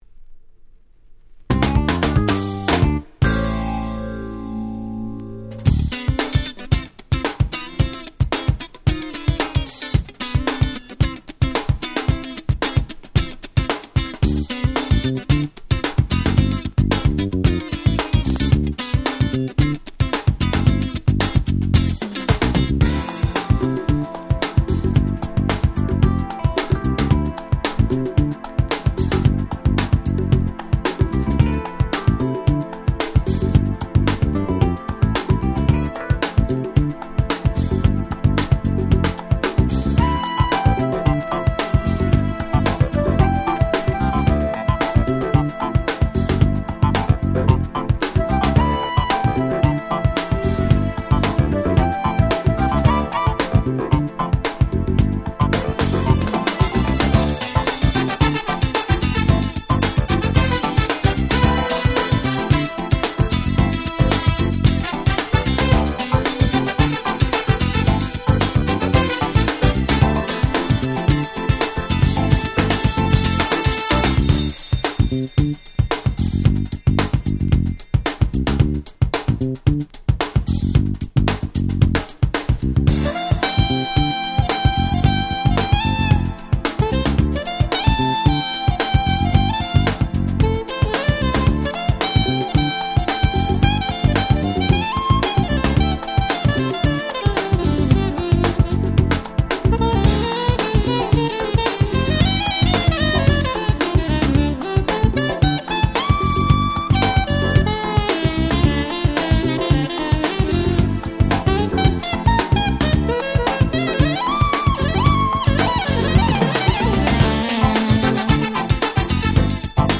92' Very Nice Acid Jazz / R&B / UK Soul !!